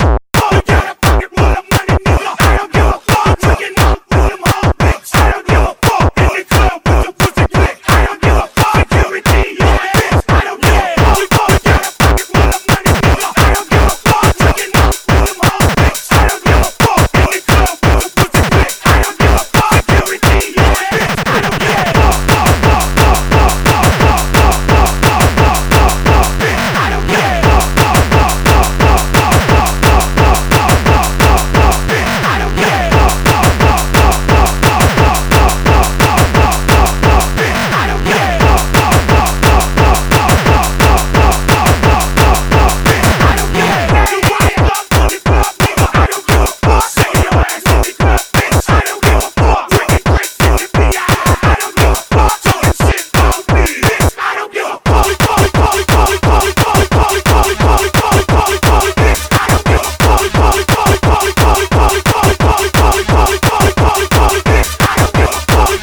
Music / Techno
hardcore techno gabber kicks